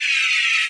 laser-beam-03.ogg